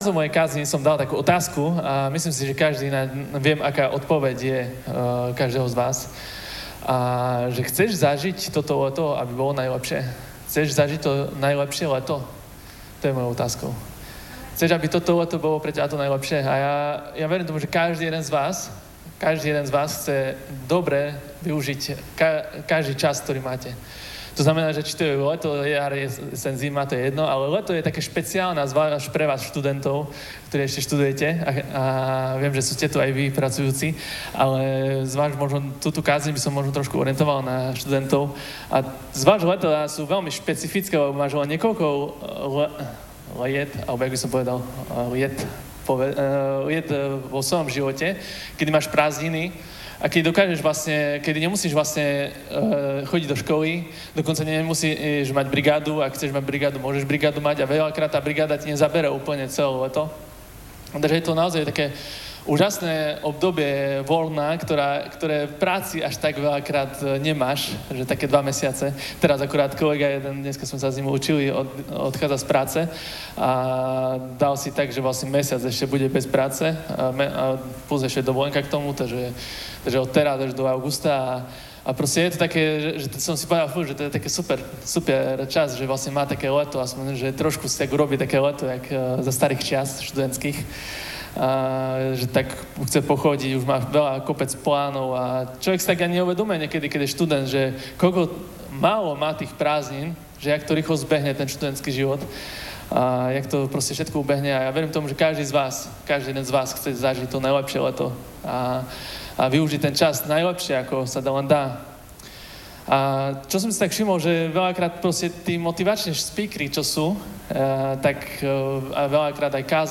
Audio kázeň